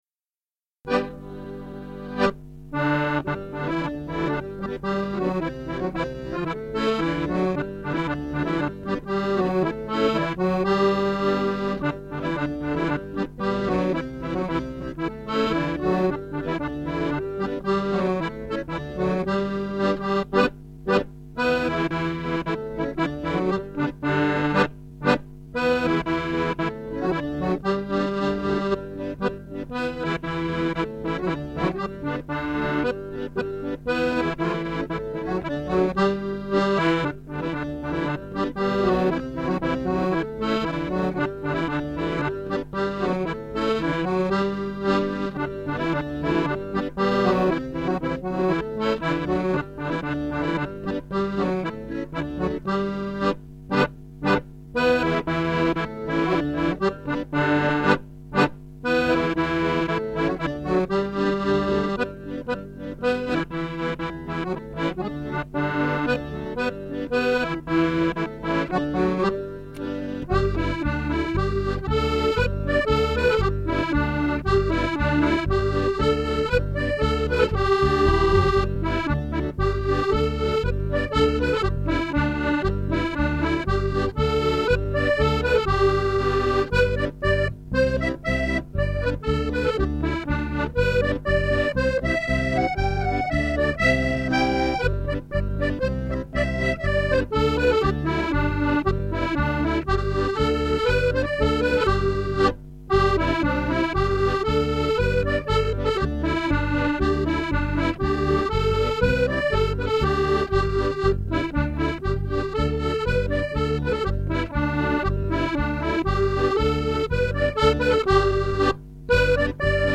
Music - 32 bar polkas, reels or jigs